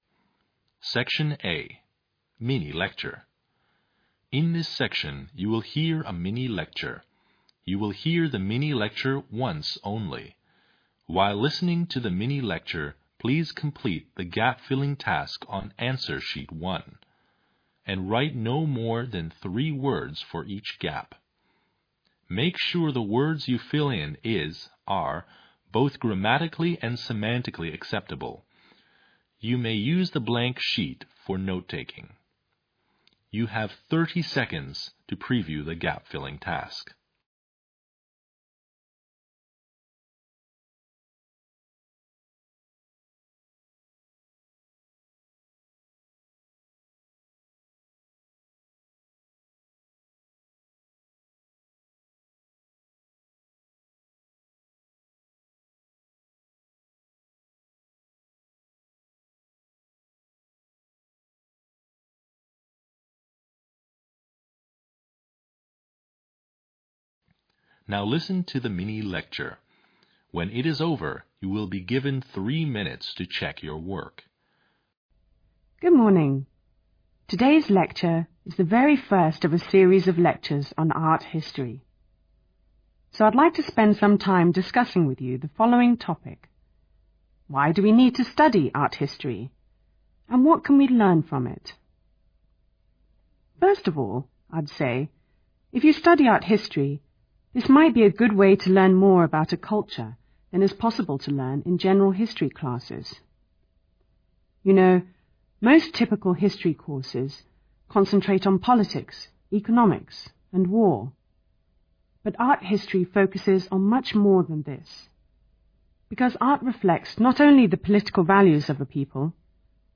Part Ⅰ listening comprehension [25min]